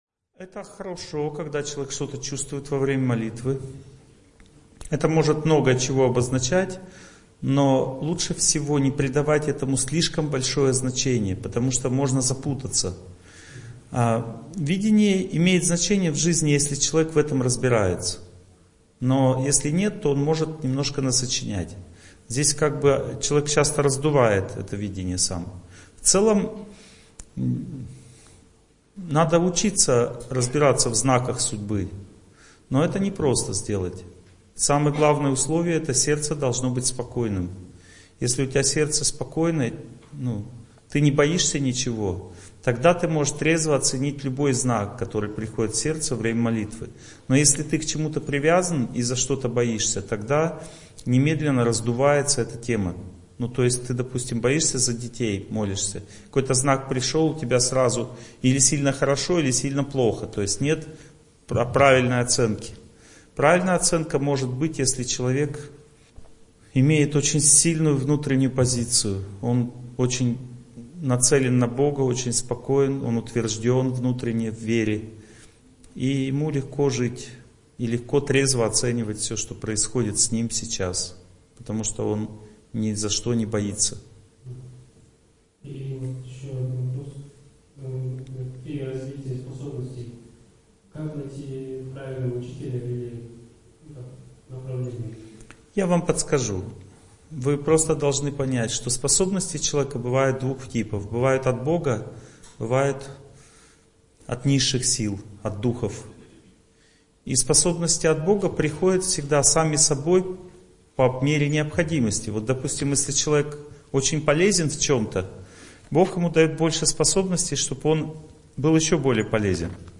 Успех во взаимоотношениях. Лекция 2
Uspeh-vo-vzaimootnosheniyah-Lekciya-2.mp3